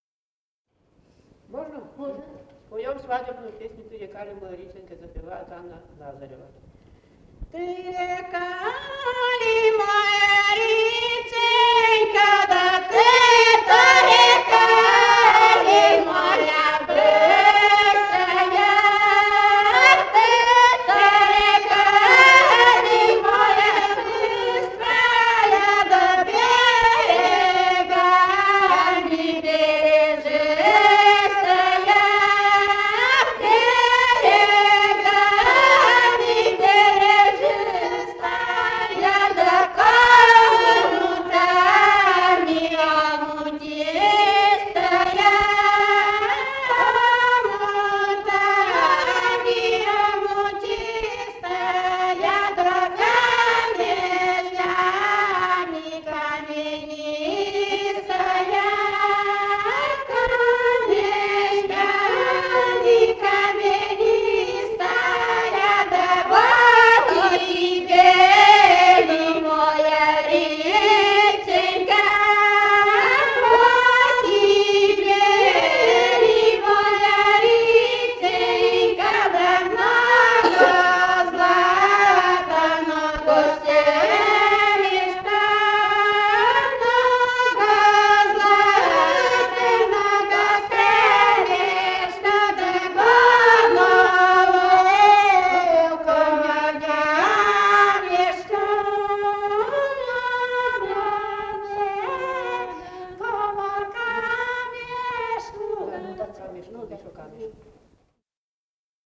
Живые голоса прошлого 016. «Ты река ли, моя реченька» (свадебная).